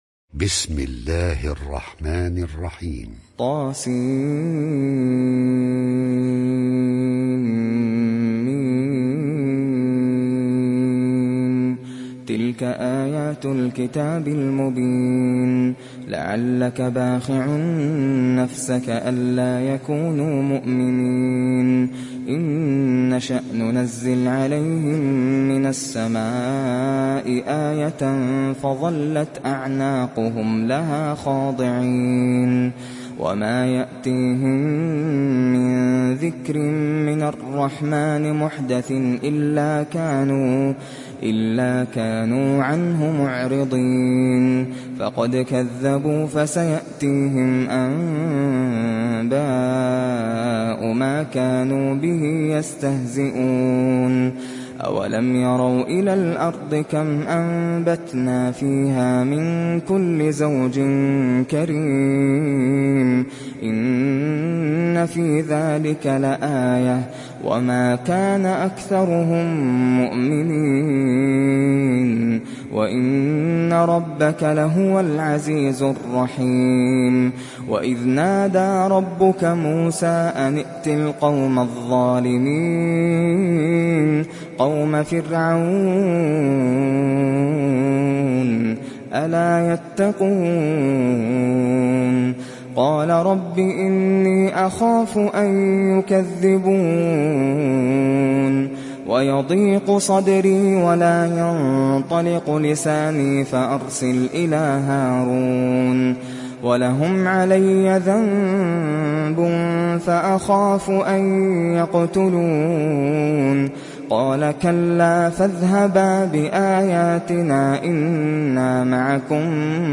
تحميل سورة الشعراء mp3 بصوت ناصر القطامي برواية حفص عن عاصم, تحميل استماع القرآن الكريم على الجوال mp3 كاملا بروابط مباشرة وسريعة